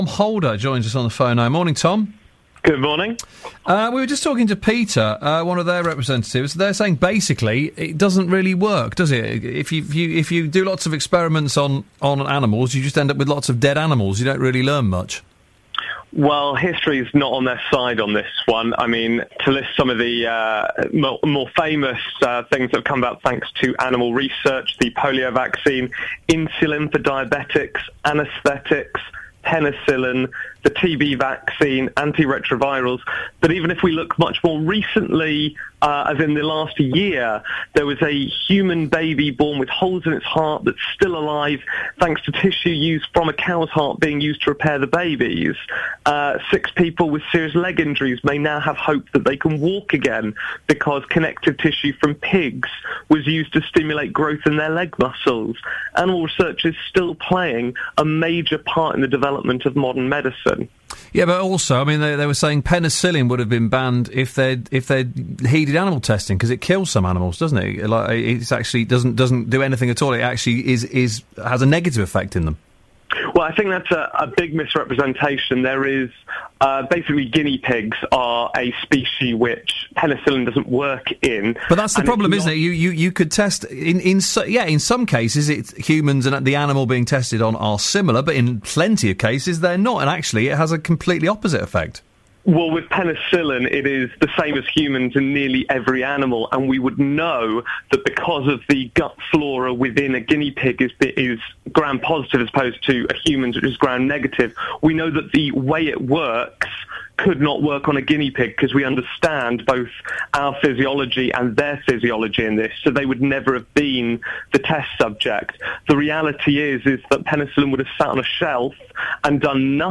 1 August 2014 – RADIO – BBC Radio Hereford and Worcestershire (UK)